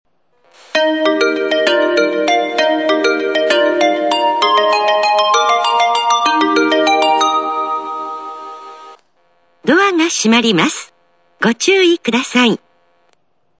スピーカー：小VOSS
音質：A
発車メロディー　(67KB/13秒)   恋の通勤列車 0.9 PCM
放送・放送設備 小vossマイナーチェンジ版スピーカーから、綺麗なメロディーが流れます。
上下ともに同じ曲ですが、３番線上りホームの方が音質は良く、下り線はノイズがのっています。